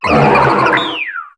Cri de Cryptéro dans Pokémon HOME.